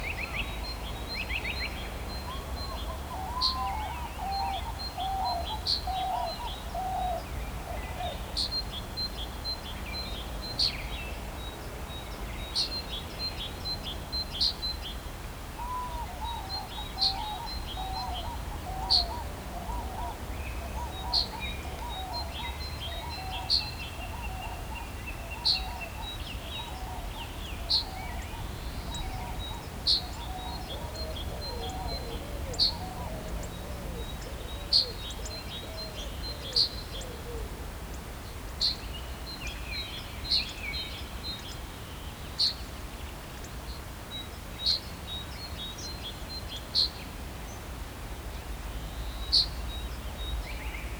BackgroundSound_Ambient.wav